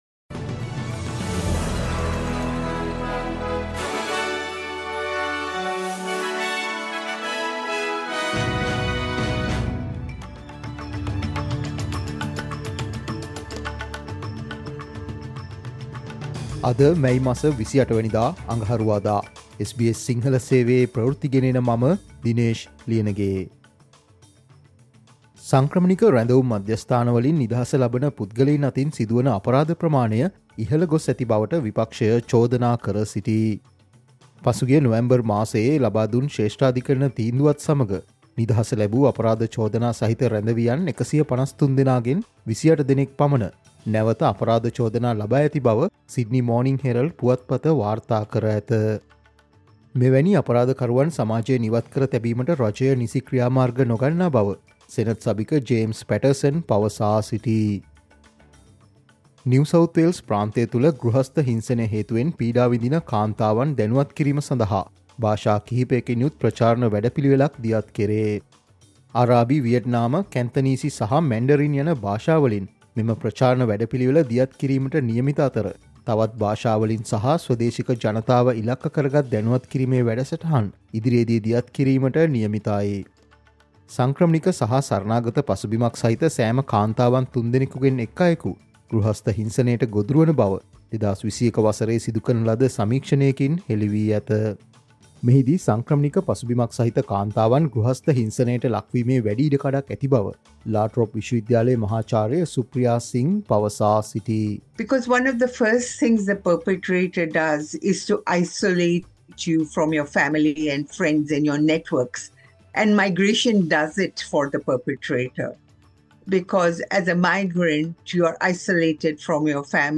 Australia news in Sinhala, foreign and sports news in brief - listen, today – Tuesday 28 May 2024 SBS Radio News